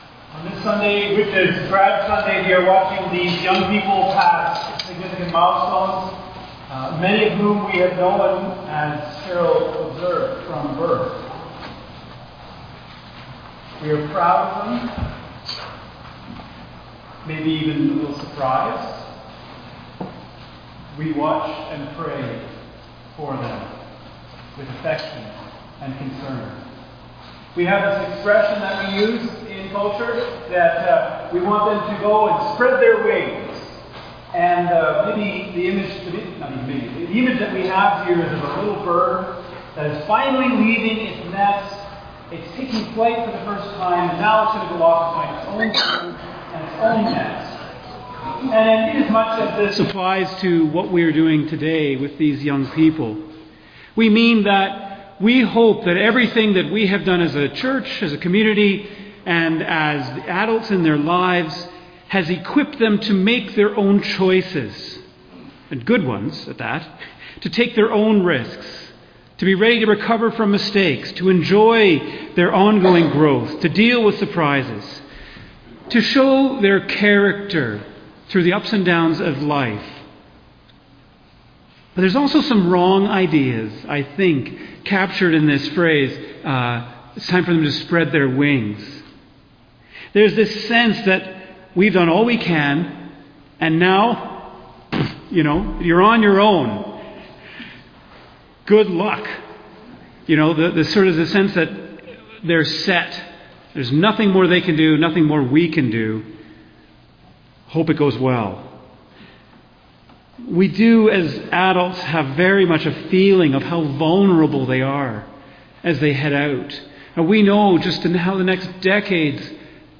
(audio improves after 45 seconds)